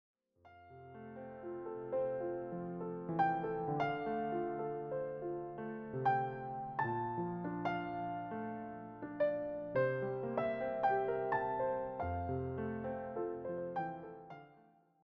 warm and familiar piano arrangements
solo piano